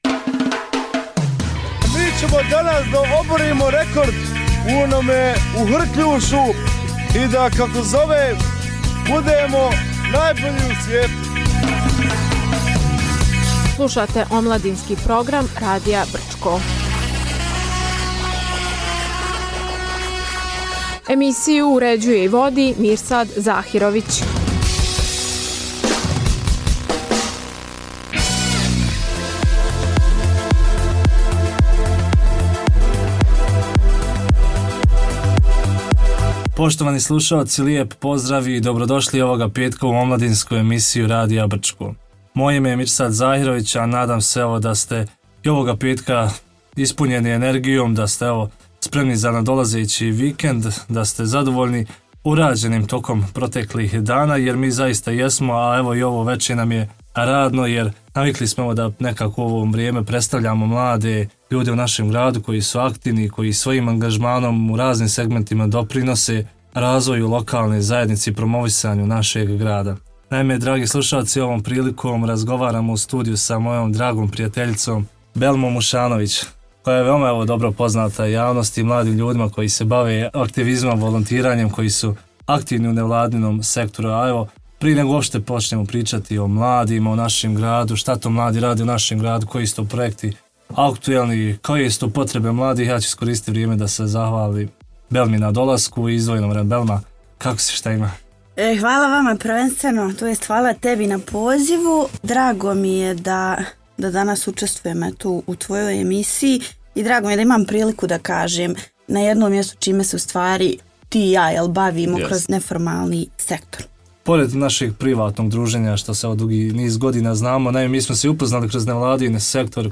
U “Omladinskoj emisiji” poslušajte razgovor